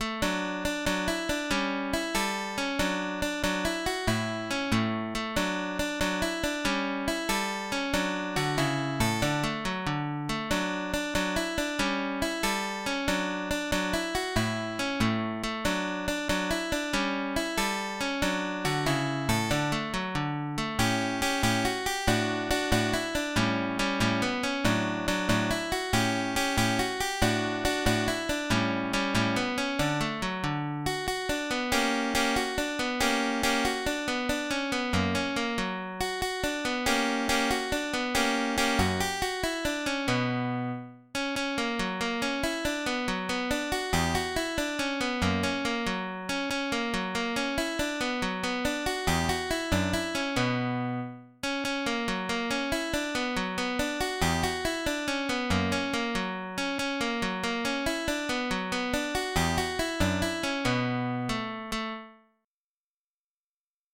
CONTRADANCE
Carulli_op40_3Allegretto.mid.mp3